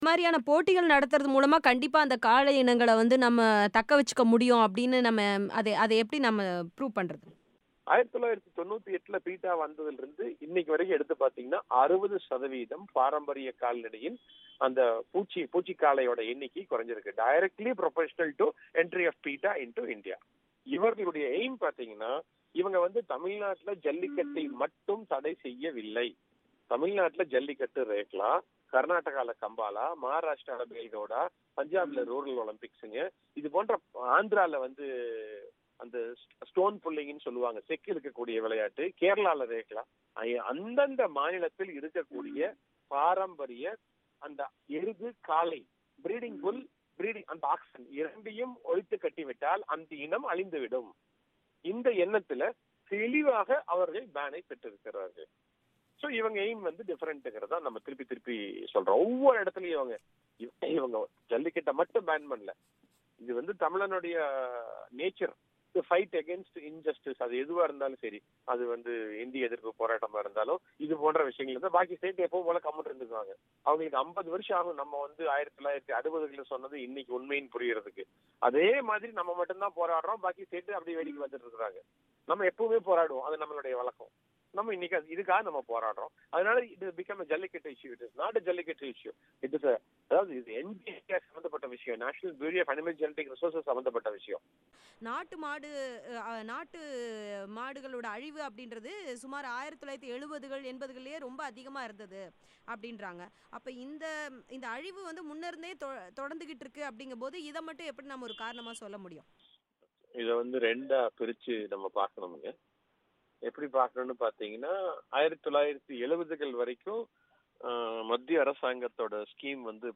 அவர் பிபிசிக்கு அளித்த பேட்டி இது